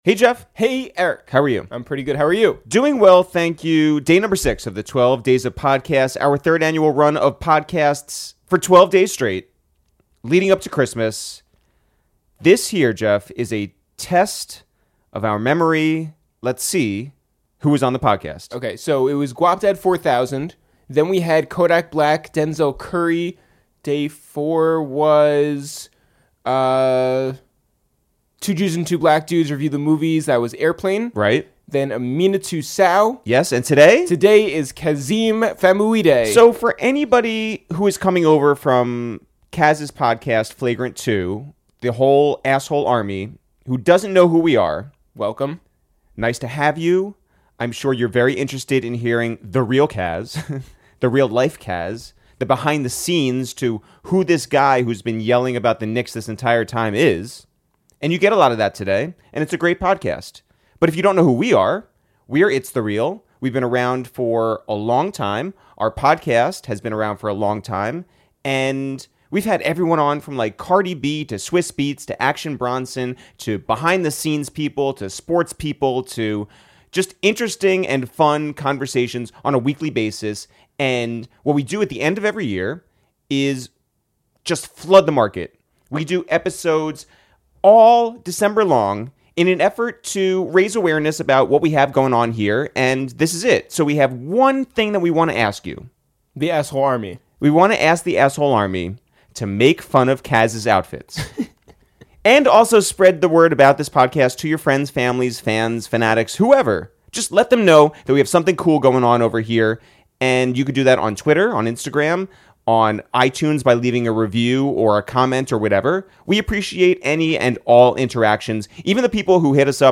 to the Upper West Side for a wide-ranging and personal conversation!